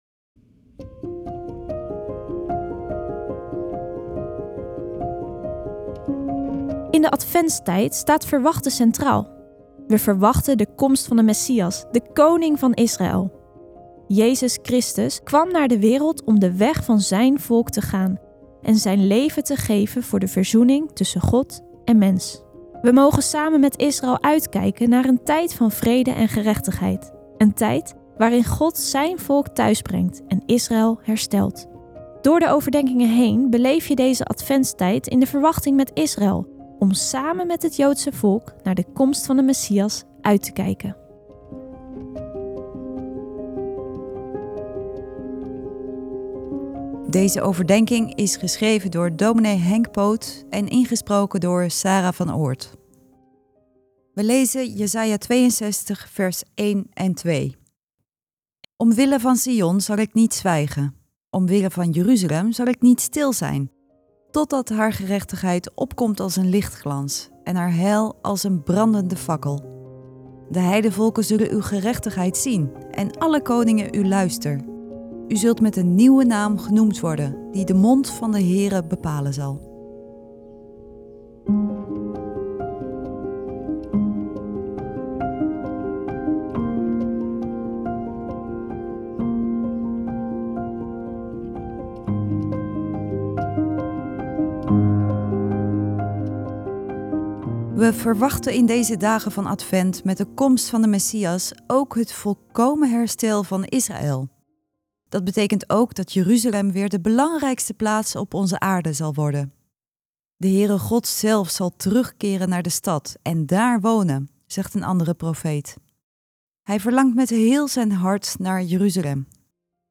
Elke dag luister je een Bijbelgedeelte met daarbij een korte overdenking.